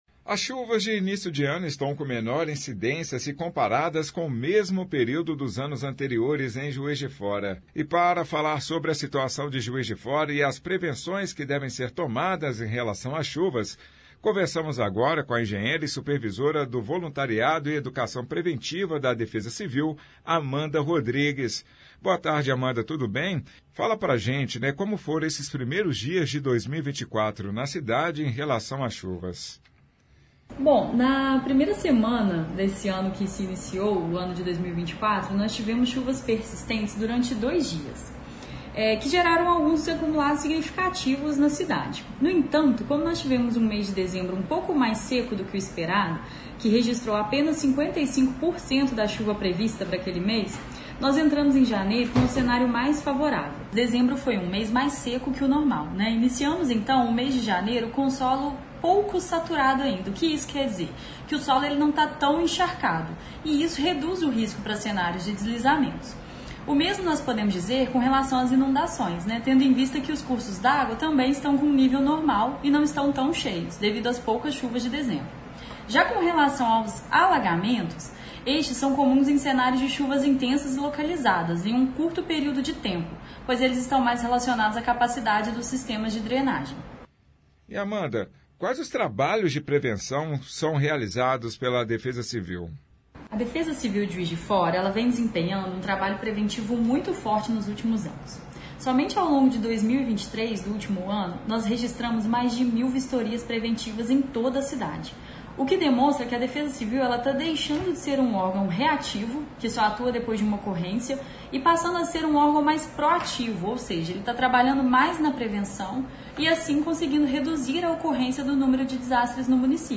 Itatiaia-Entrevista-Defesa-Civil.mp3